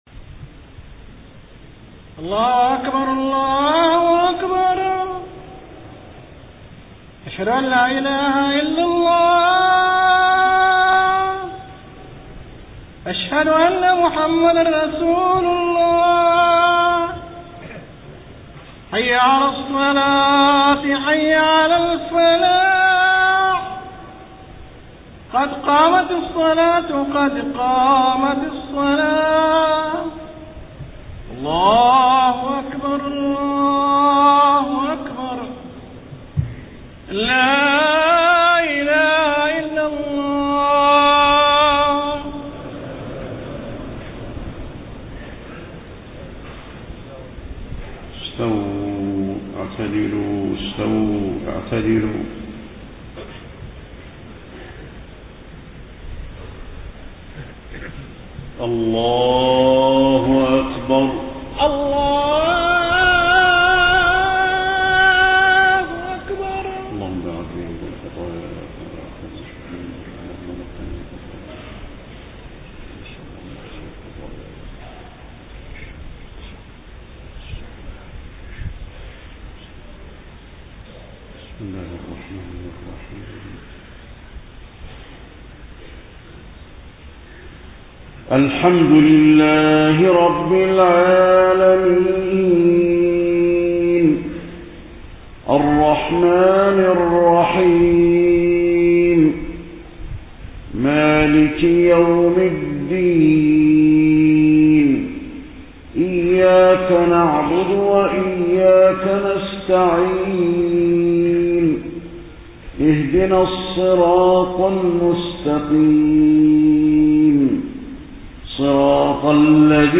صلاة العشاء 12 ربيع الأول 1431هـ سورتي البلد و الشمس > 1431 🕌 > الفروض - تلاوات الحرمين